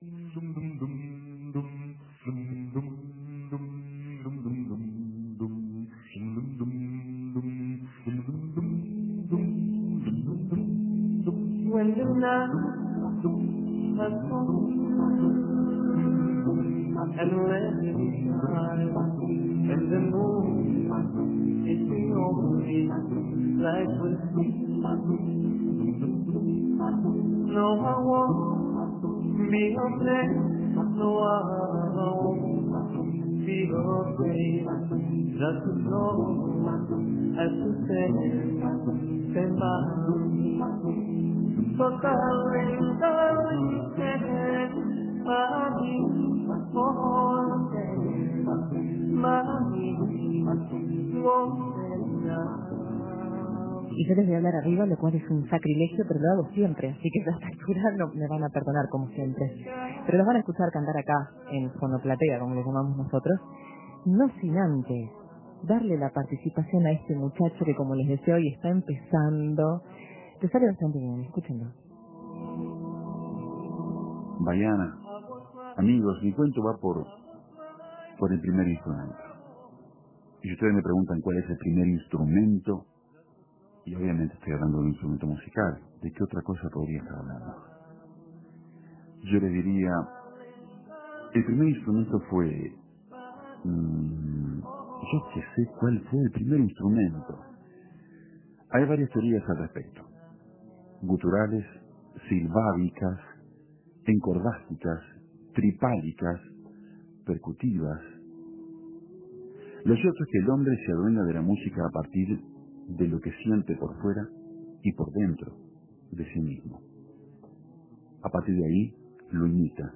Antes, estuvieron en la fonoplatea de El Espectador.